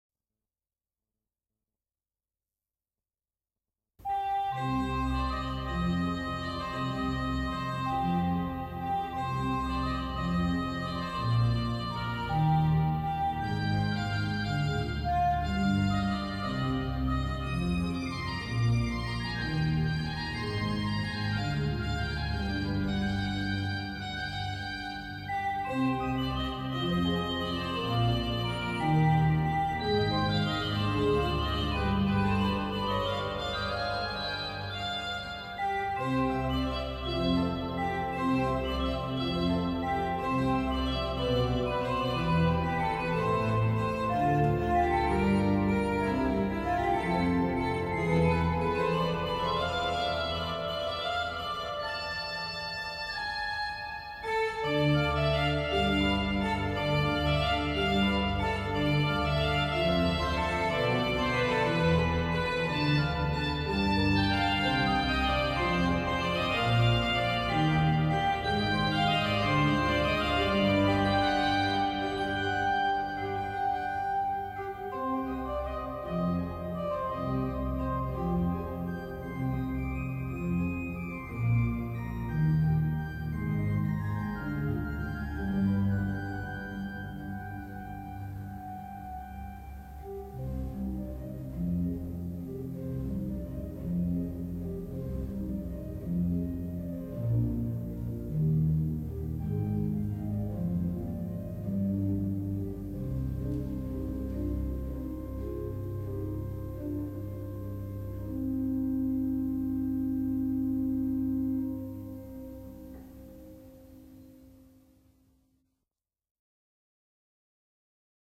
Festliche Weihnachtsmusik aufgenommen in der